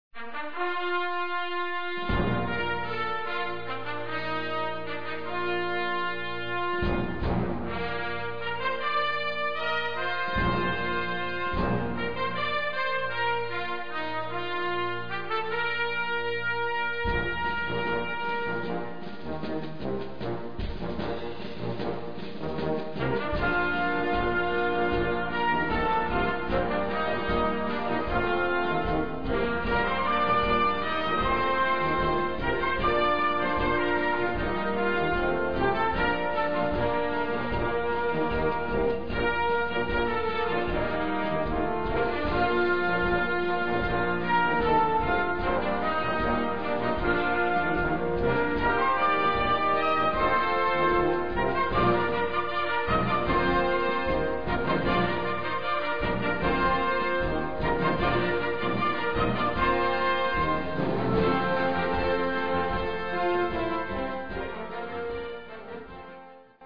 Gattung: Blasmusik für Jugendkapelle - Master Level
Besetzung: Blasorchester